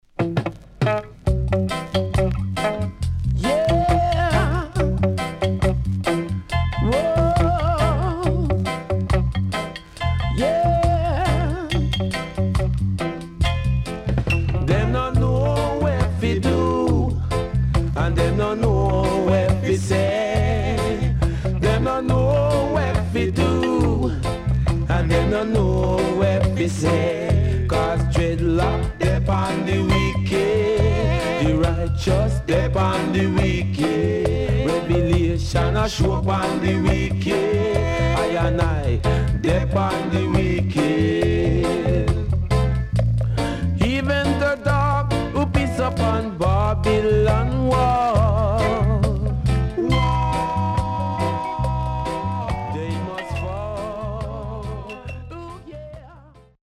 76年 Good Vocal